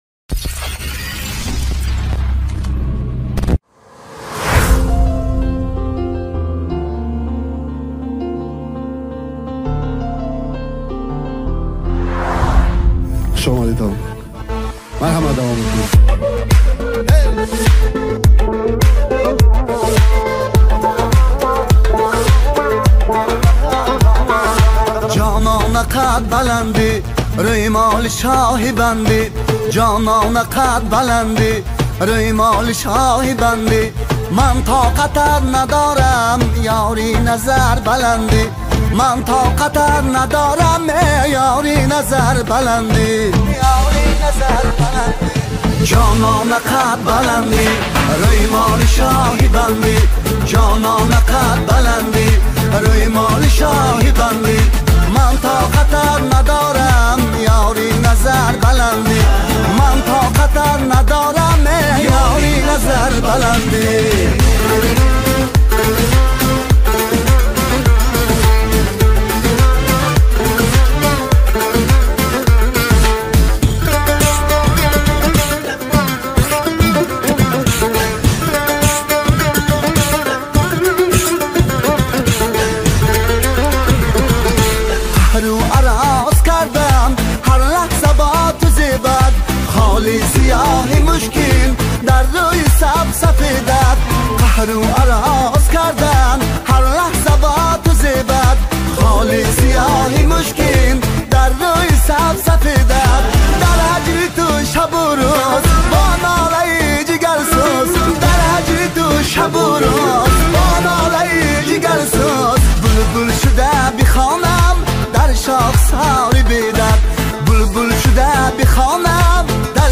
Категория: Таджикские